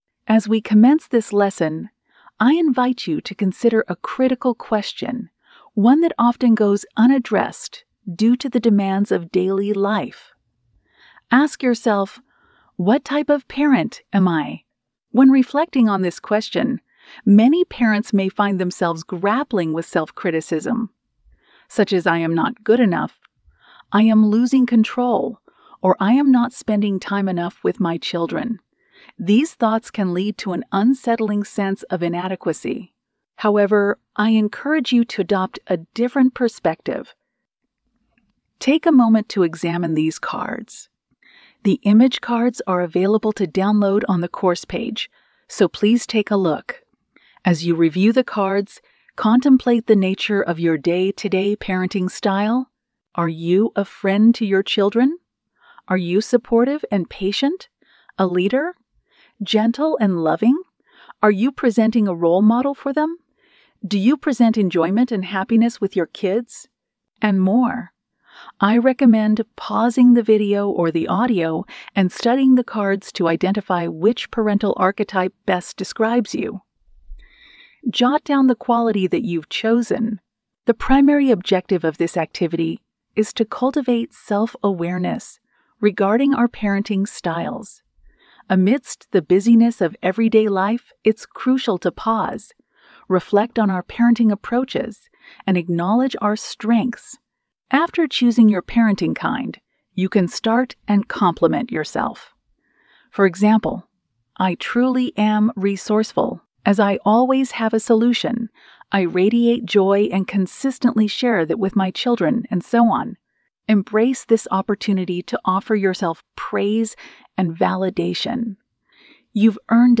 Lecture 2 | Empowering Parents